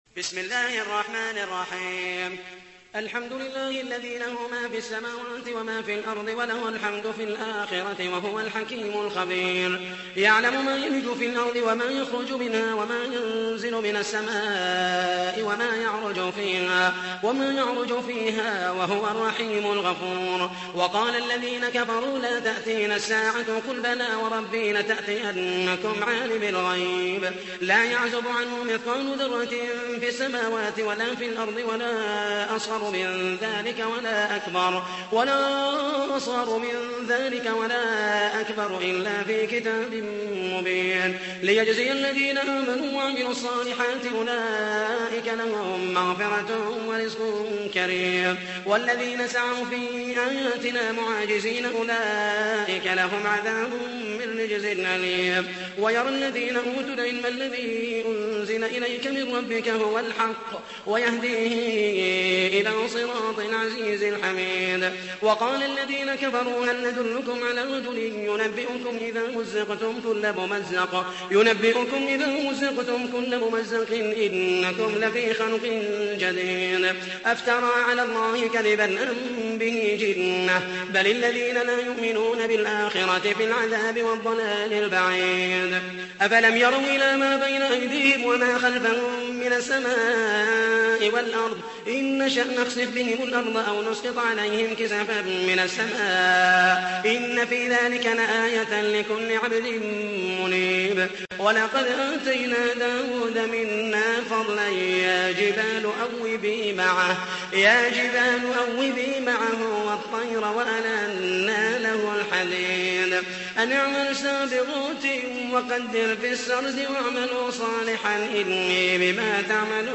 تحميل : 34. سورة سبأ / القارئ محمد المحيسني / القرآن الكريم / موقع يا حسين